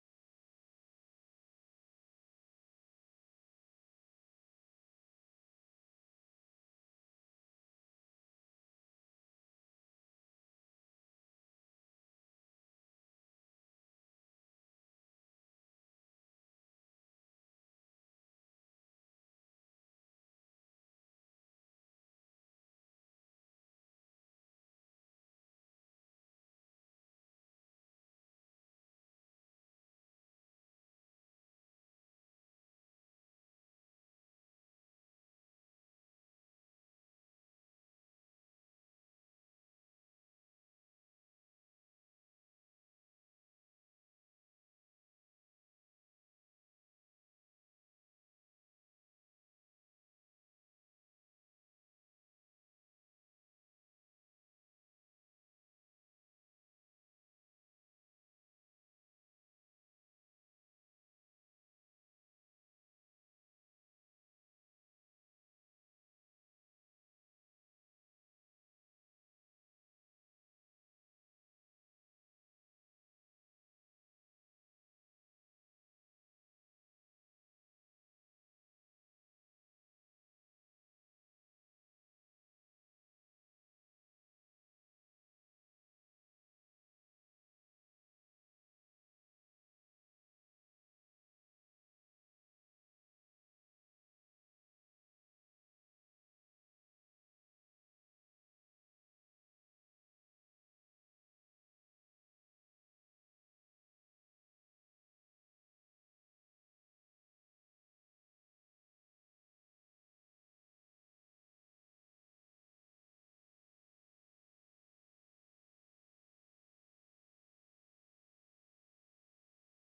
Gemeenteraad 31 mei 2022 20:30:00, Gemeente West Betuwe
Download de volledige audio van deze vergadering